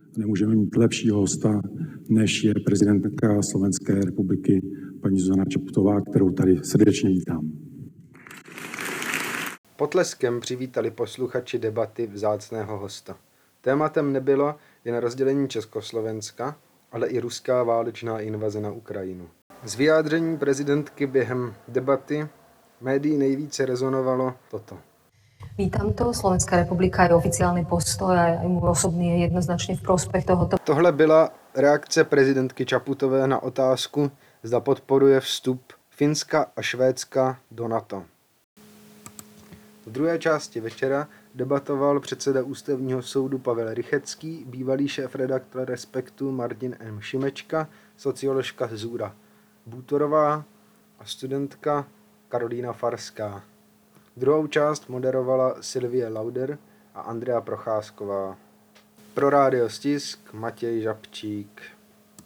V brněnském Mahenově divadle v pondělí diskutovala prezidentka Slovenské republiky Zuzana Čaputová se šéfredaktorem týdeníku Respekt Erikem Taberym. Brno si organizátoři vybrali protože právě tam se Václav Klaus a Vladimír Mečiar dohodli na rozdělení tehdejšího Československa.
Rozděleni a přesto spolu. V Mahenově divadle diskutovala Čaputová s Taberym